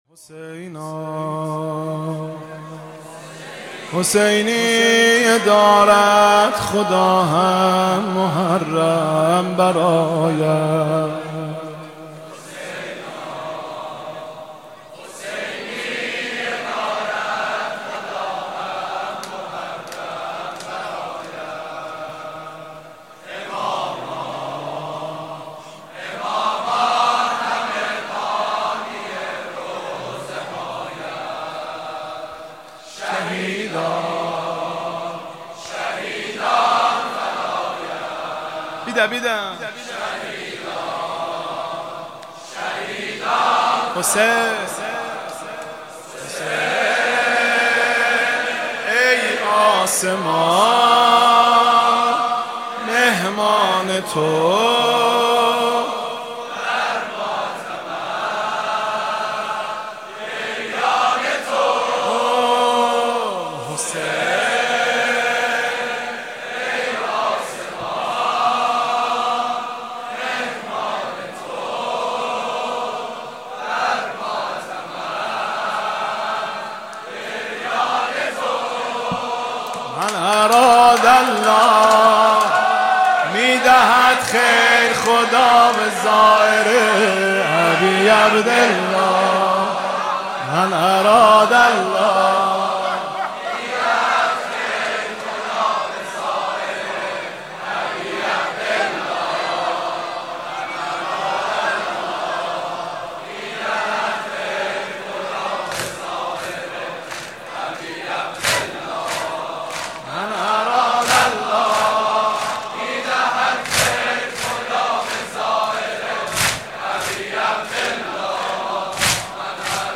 حاج مهدی رسولی
محرم1402 شب هشتم -نوحه - حسینا - مهدی رسولی
محرم1402 شب هشتم